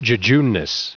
Prononciation du mot jejuneness en anglais (fichier audio)
Prononciation du mot : jejuneness